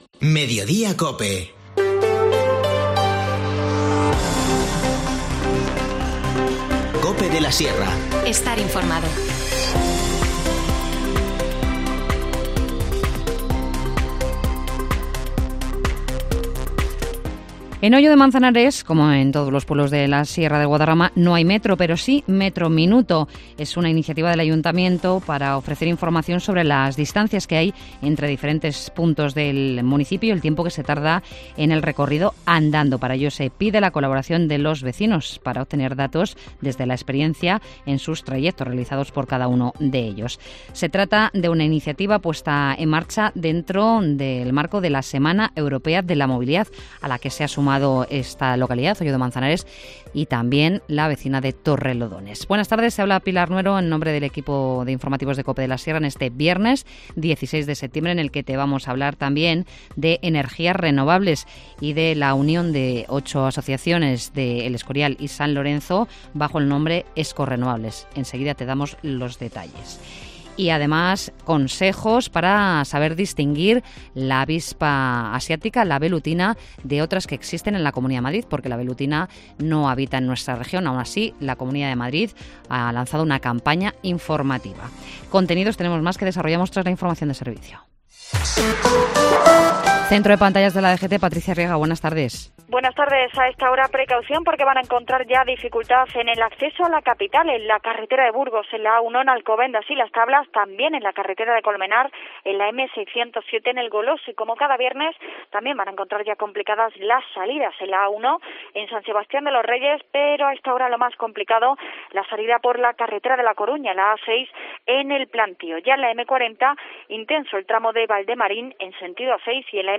Informativo Mediodía 16 septiembre